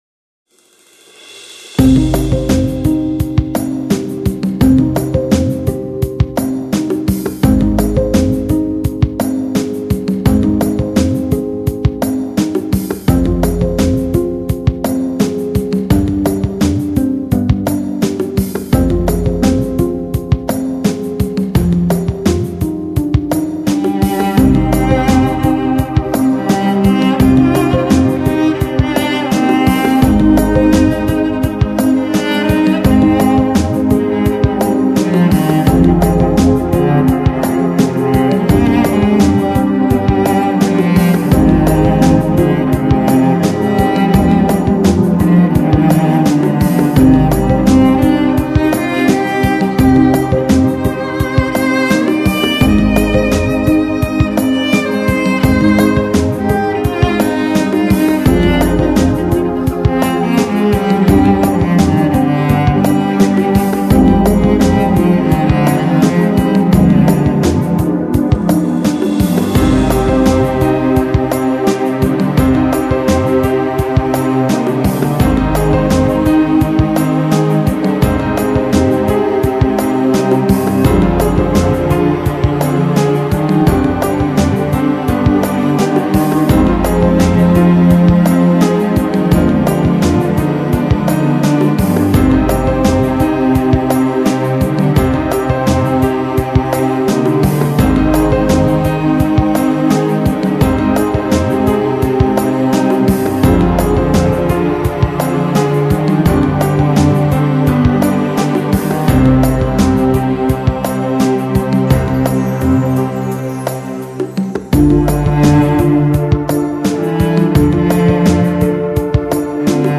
你将发现听他的音乐是一次完全新的经历。它刺激，安慰 并且迷惑倾听者"